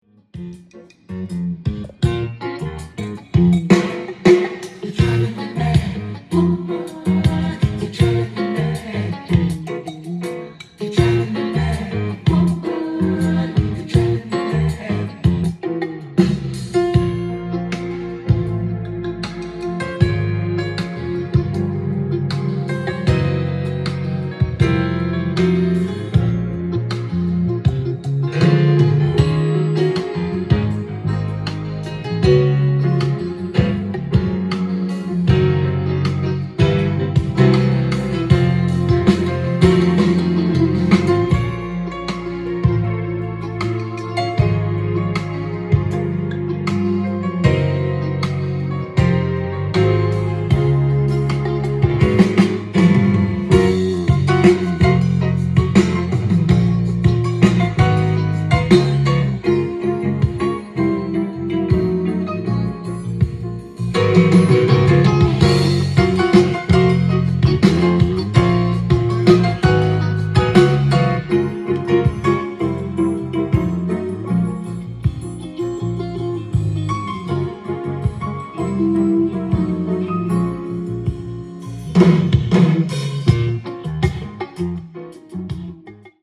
店頭で録音した音源の為、多少の外部音や音質の悪さはございますが、サンプルとしてご視聴ください。
軽やかなタッチと透明感のある音色を聴かせる正統派ジャズ・ナンバー